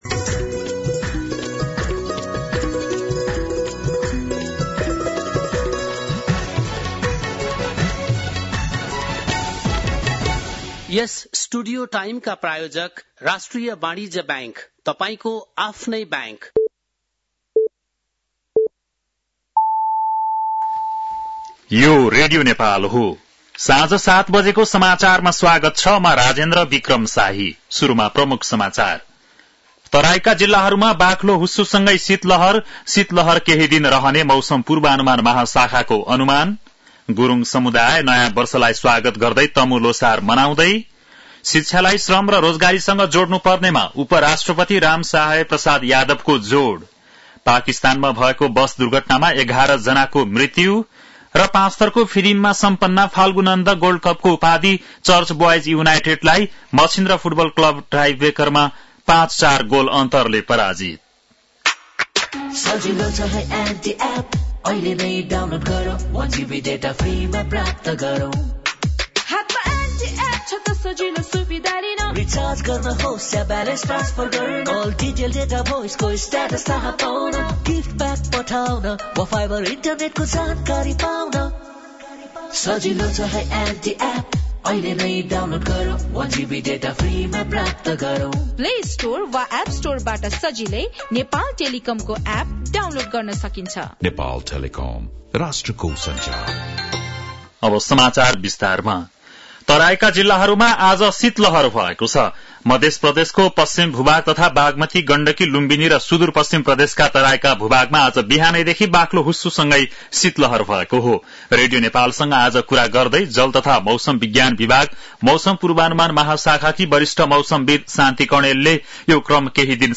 बेलुकी ७ बजेको नेपाली समाचार : १६ पुष , २०८१
7-PM-Nepali-News-9-15.mp3